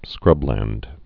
(skrŭblănd)